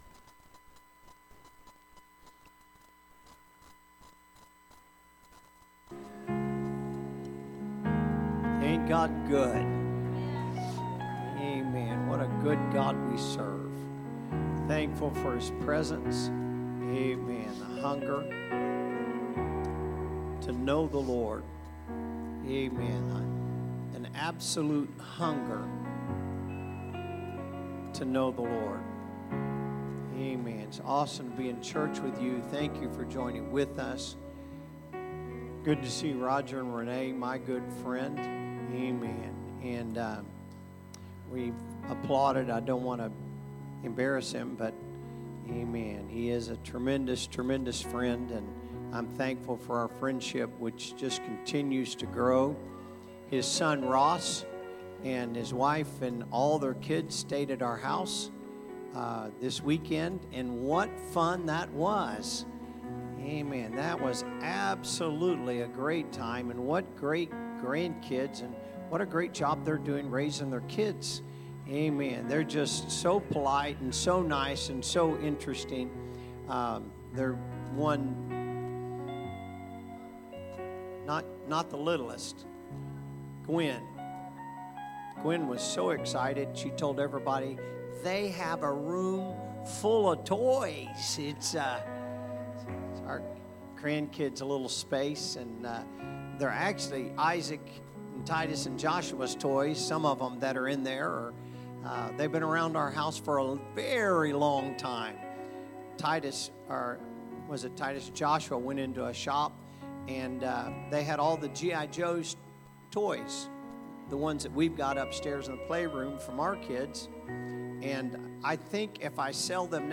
Sunday Service - Part 19